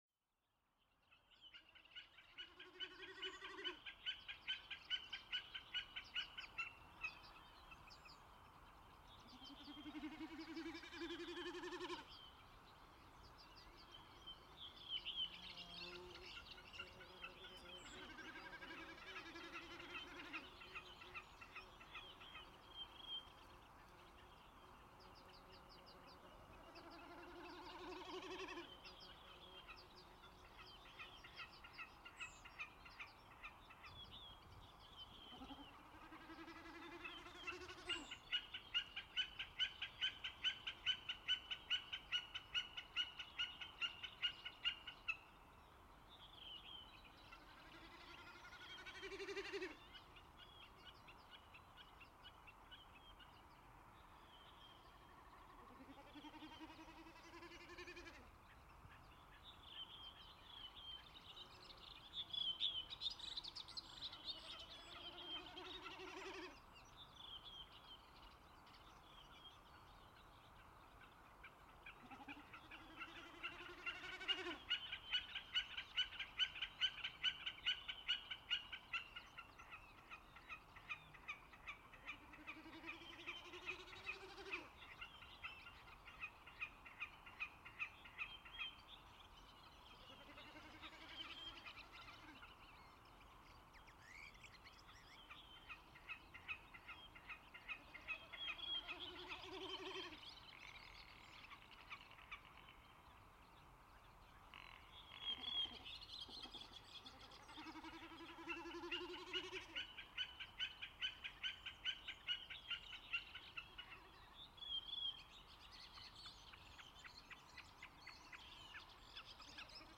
I placed the microphones 30 meters from the tent, started recording and fell asleep.
It is nice soundscape of early spring in Iceland without noise from human activity. Many bird species are audible in this recording. Common snipe, Whimbrell, Red-tailed Godwit, Common Redshank, European Golden Plover, European, Oystercatcher, Whooper Swan, Redwing, Meadow Pipit and Rock Ptarmigan.
Quality headphones are recommended while listening at low level.